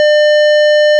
stall-600-continue.wav